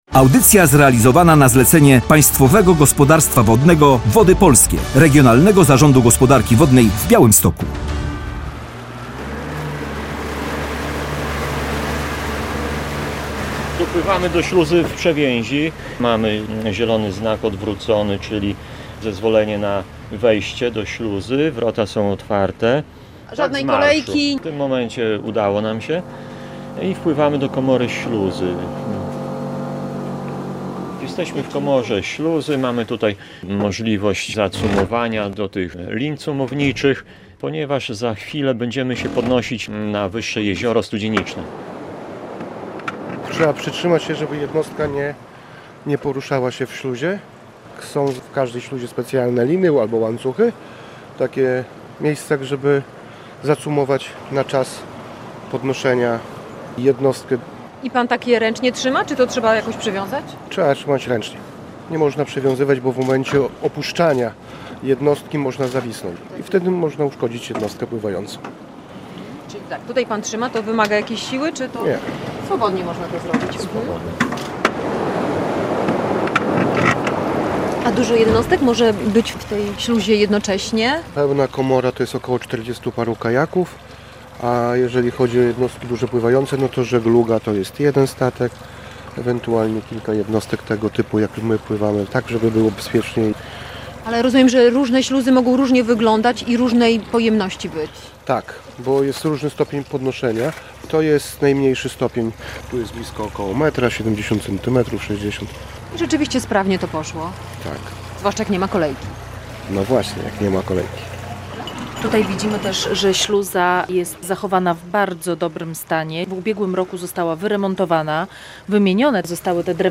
Prześluzujemy się pomiędzy jeziorami Studzienicznym i Białym. Opowiemy, jak działa taka śluza i jakich błędów w czasie śluzowania nie popełniać.